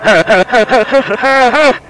Backwards Laughter Sound Effect
Download a high-quality backwards laughter sound effect.
backwards-laughter.mp3